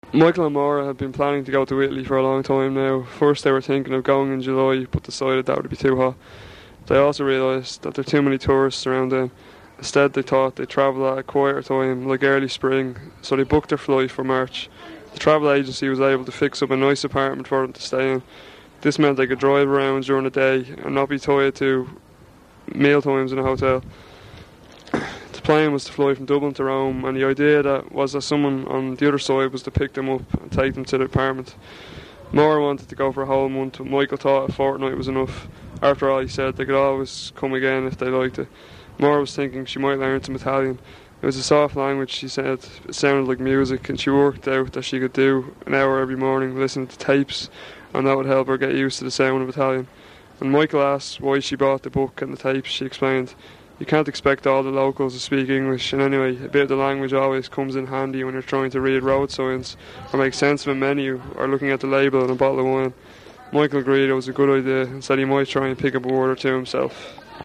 Dublin English - Text passages
The informants all read a standard (fictitious) text which I offered to them when doing recordings.
Local Dublin speaker from North Side of city